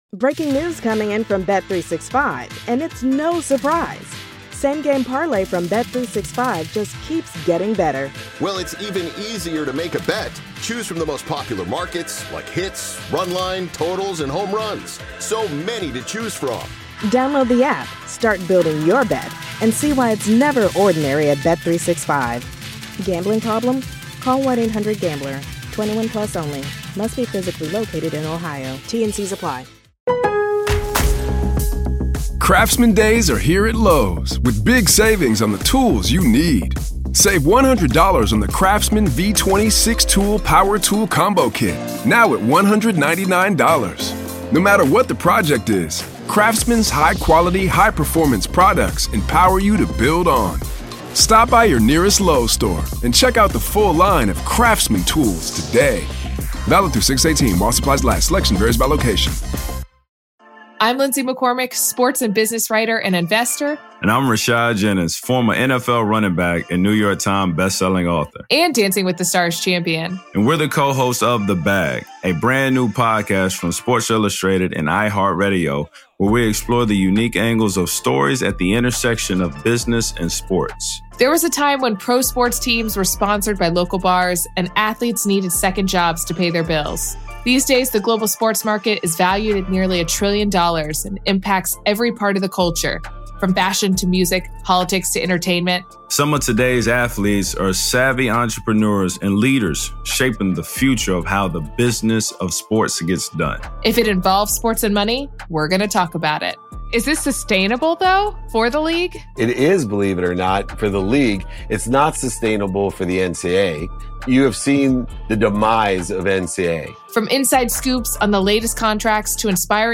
Breakaway is a podcast miniseries that explores the NBA from the inside out. Every episode delves into one of the driving forces of the game, featuring the perspectives of the players, coaches, and thinkers who understand it best.